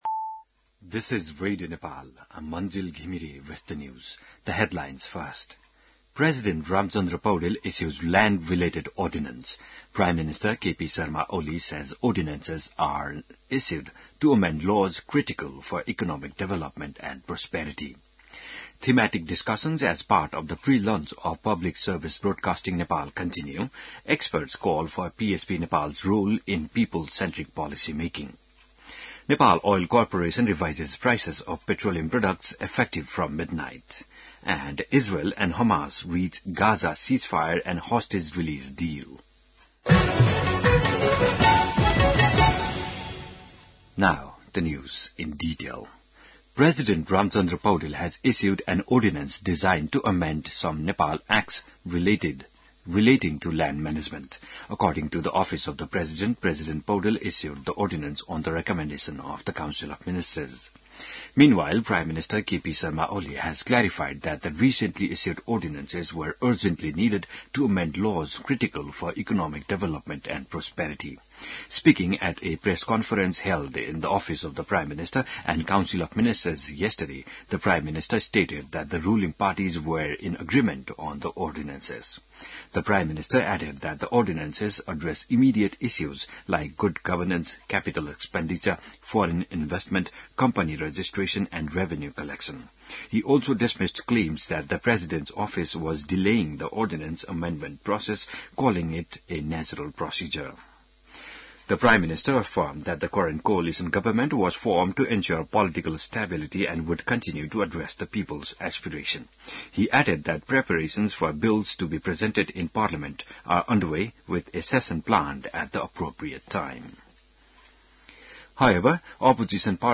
बिहान ८ बजेको अङ्ग्रेजी समाचार : ४ माघ , २०८१